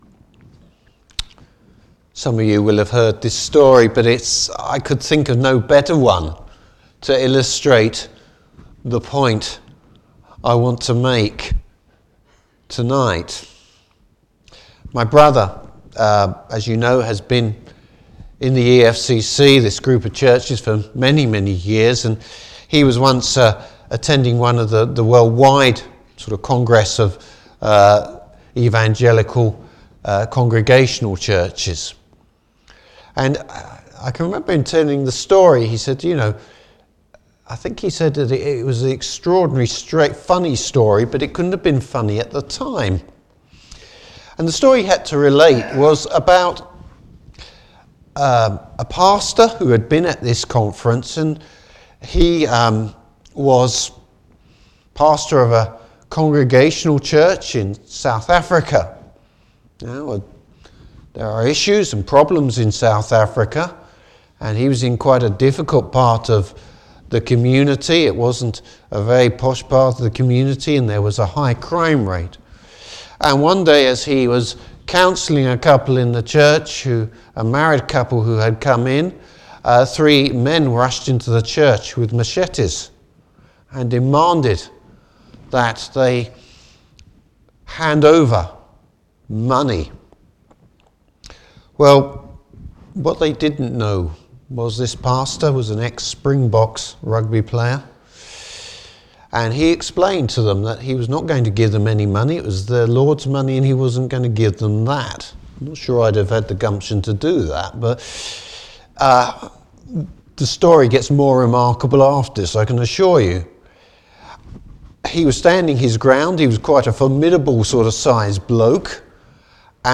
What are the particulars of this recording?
Service Type: Morning Service Bible Text: 2 Corinthians 4.